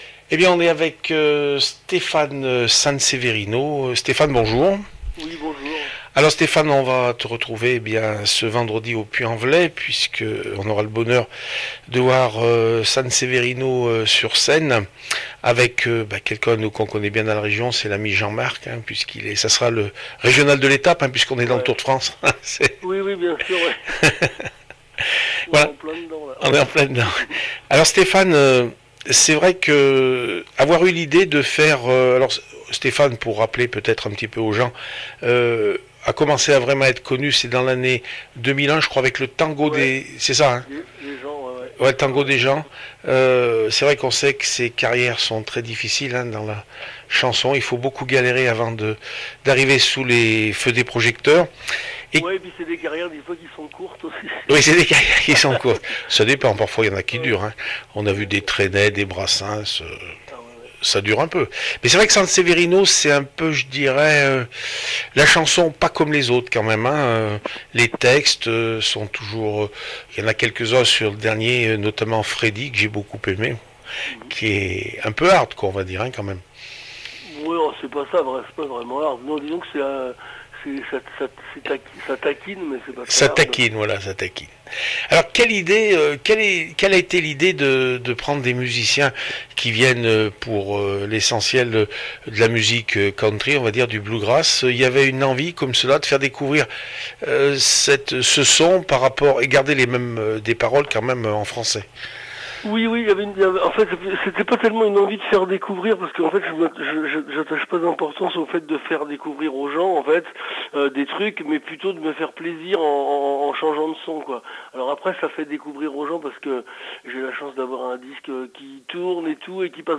CONFERENCE DE PRESSE SANSEVERINO